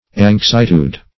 Search Result for " anxietude" : The Collaborative International Dictionary of English v.0.48: Anxietude \Anx*i"e*tude\, n. [L. anxietudo.] The state of being anxious; anxiety.